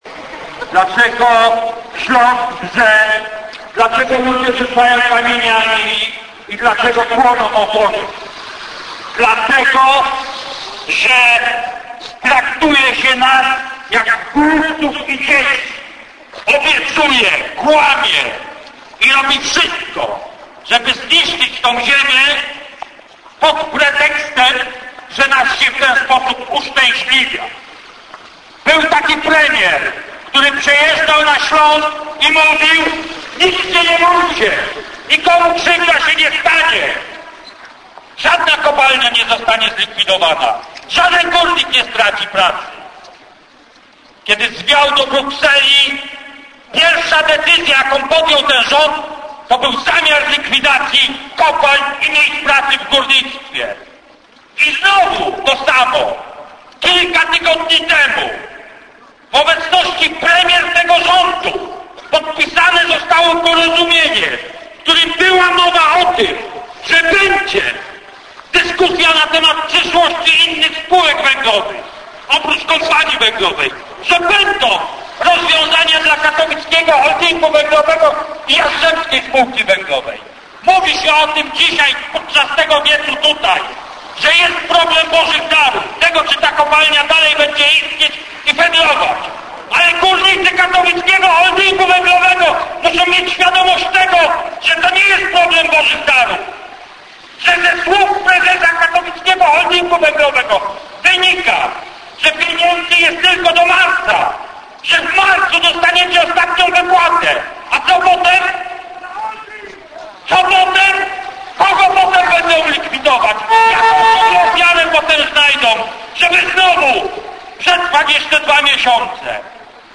Dlaczego Ślask wrze (przemówienie pod kopalnią Boże Dary)